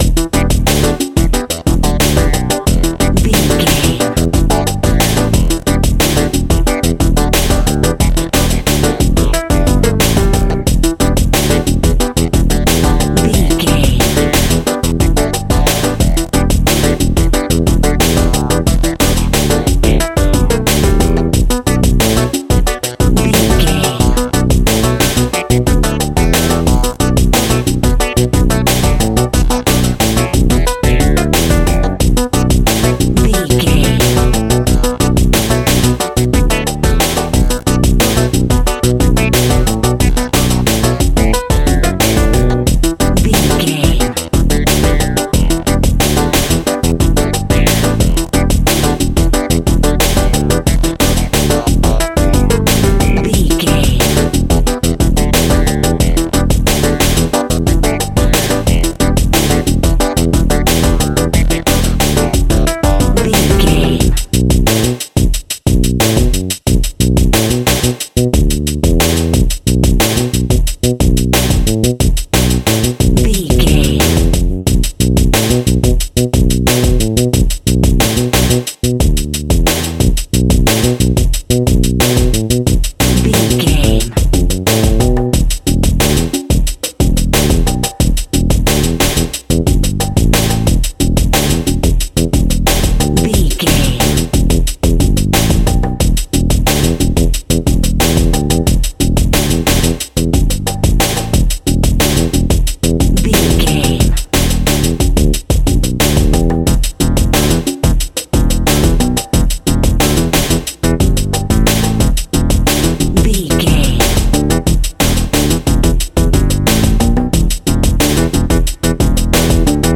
Aeolian/Minor
soul jazz
funky jazz music
electric guitar
bass guitar
drums
hammond organ
fender rhodes
percussion